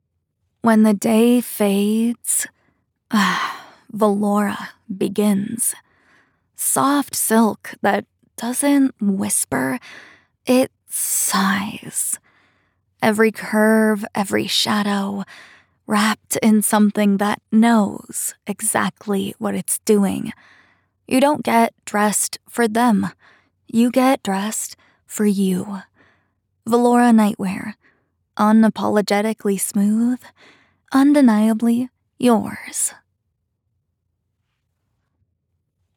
Commercial // Smooth, Sultry, Chesty